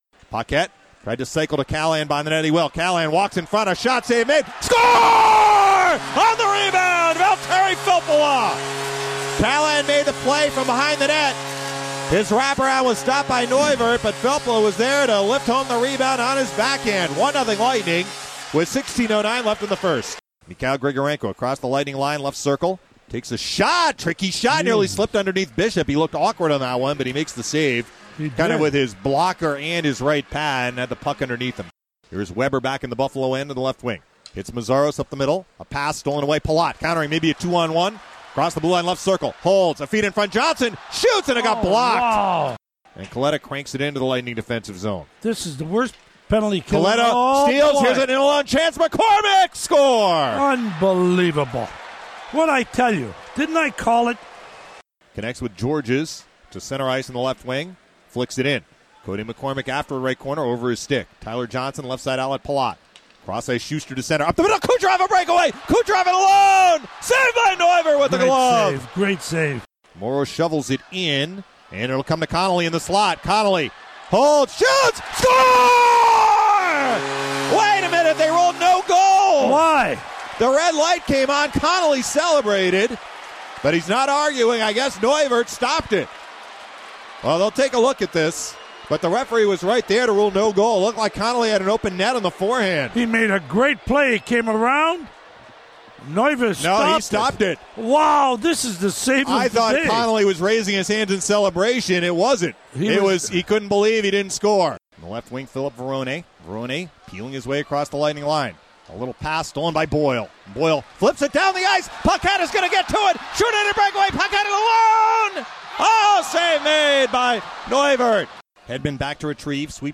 Game Highlights from the 1-9-15 match vs. Buffalo Sabres.